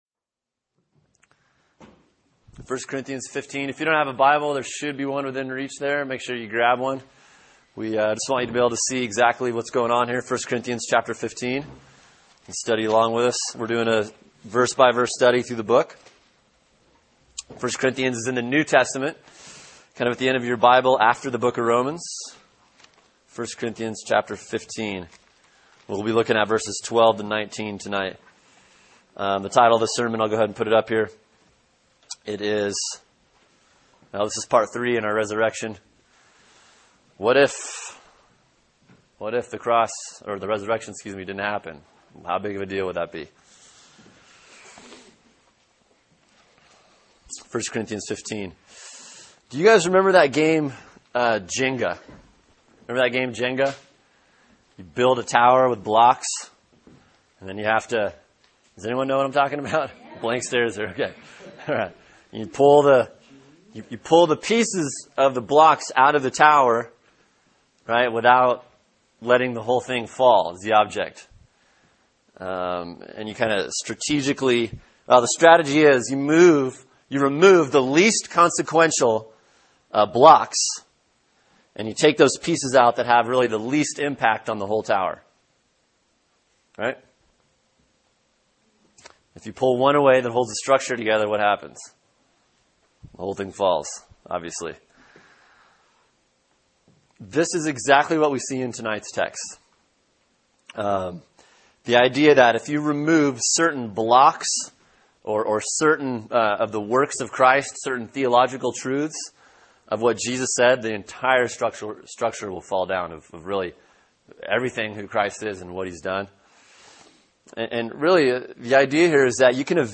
Sermon: 1 Corinthians 15:12-19 “The Resurrection, part 3” | Cornerstone Church - Jackson Hole